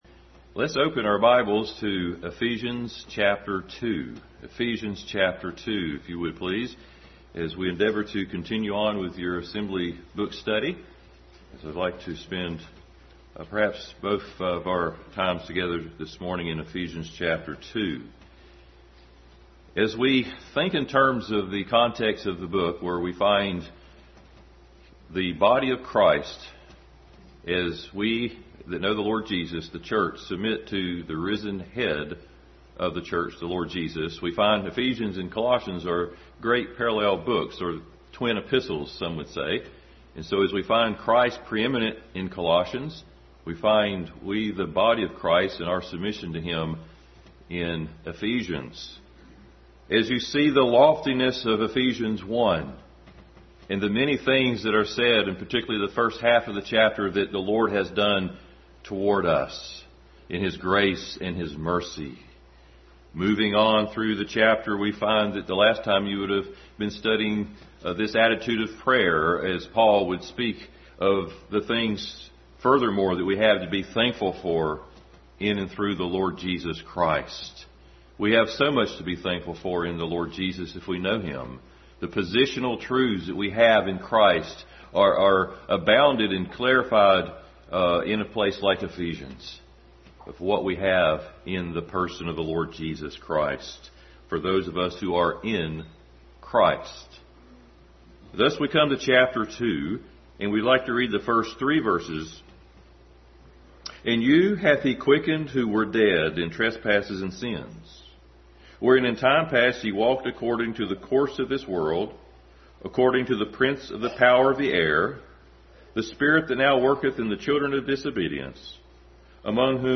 Adult Sunday School: Continued study in Ephesians.